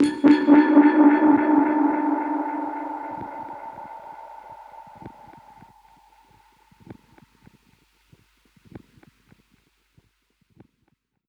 Index of /musicradar/dub-percussion-samples/85bpm
DPFX_PercHit_A_85-06.wav